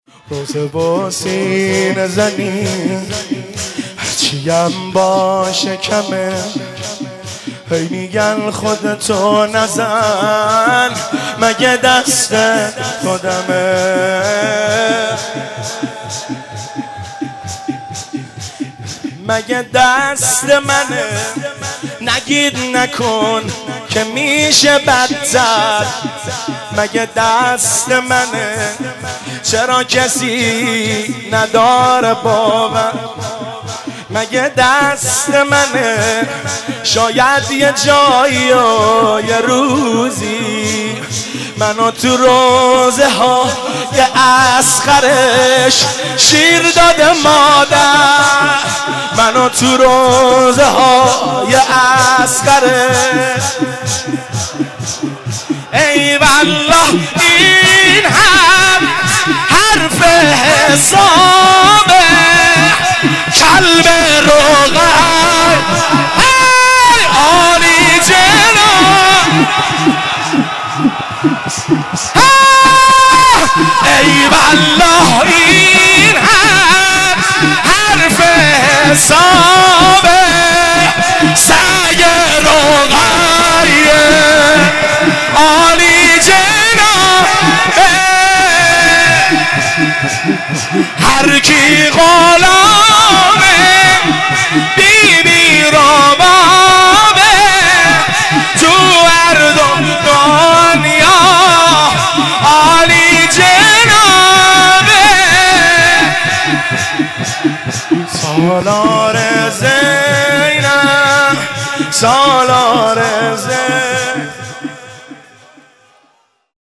روضه و سینه زنی هر چی هم باشه کمه
روضه و ذکر